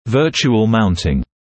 [‘vɜːʧuəl ‘mauntɪŋ][‘вёːчуэл ‘маунтин]виртуальная загипсовка, установка цифровых моделей в виртуальный артикулятор, присутствующий в соответствующей компьютерной программе